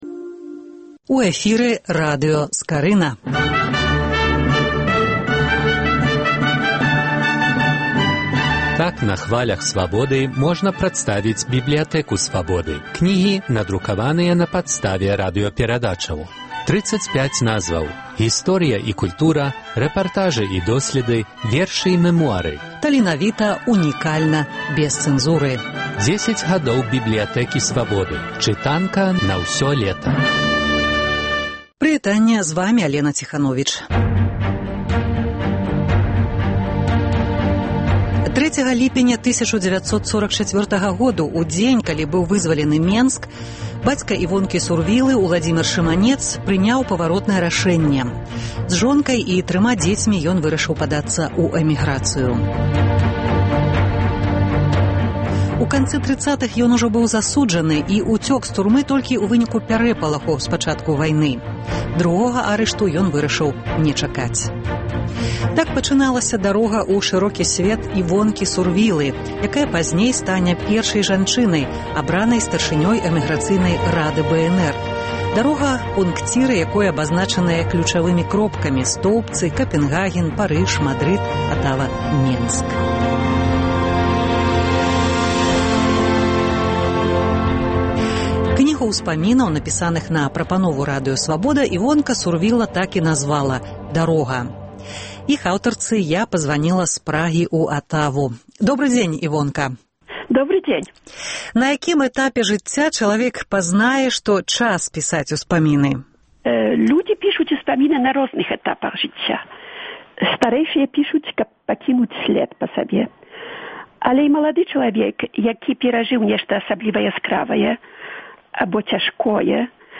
Працяг радыёсэрыі “10 гадоў “Бібліятэкі Свабоды”. Гутарка са старшынёй Рады БНР пра кнігу яе ўспамінаў "Дарога"